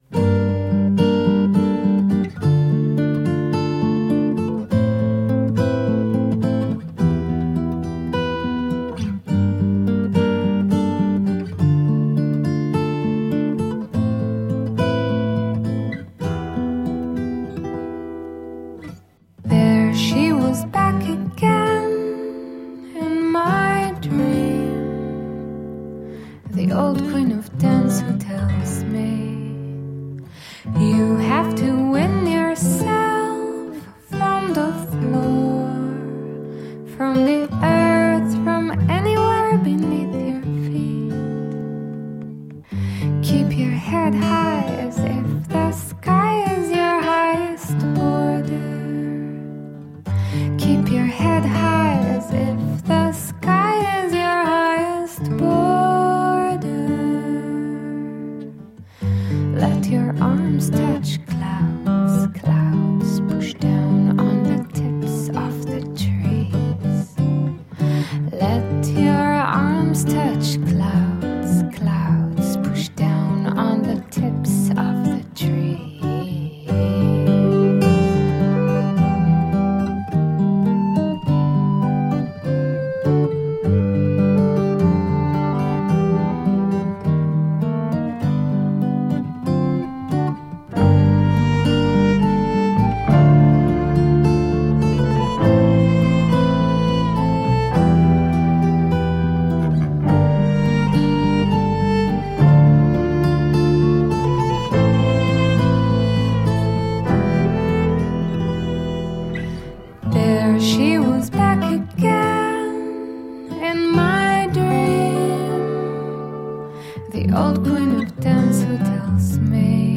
Cabaret infused rock band mixing folk and world.